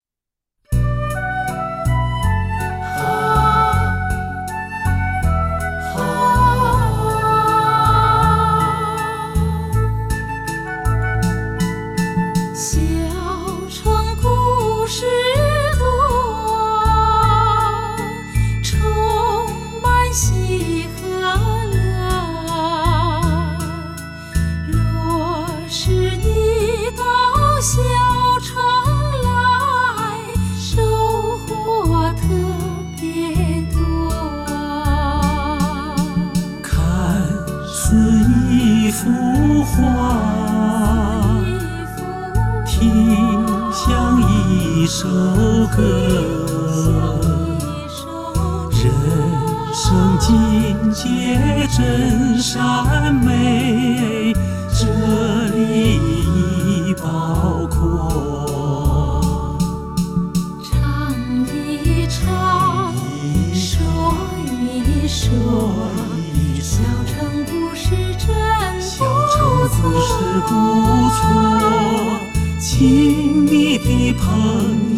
老歌新唱，感受环绕音效的包围，